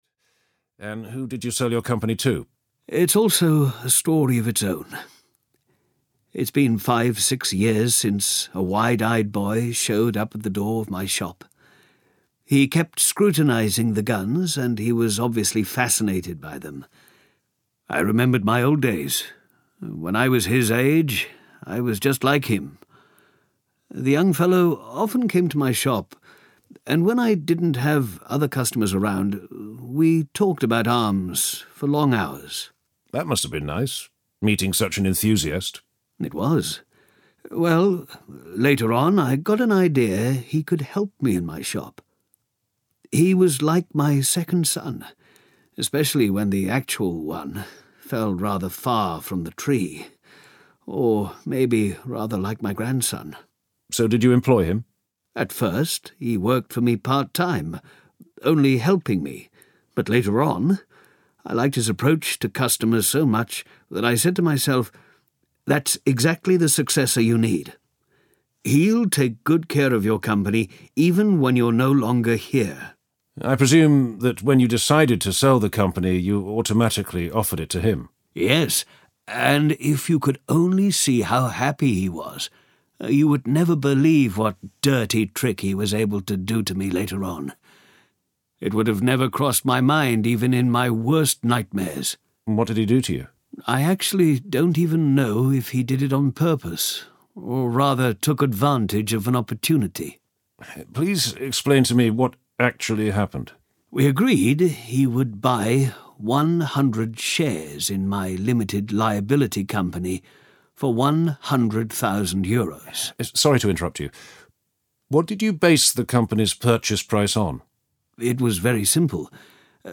Vyberte Audiokniha 97 Kč Další informace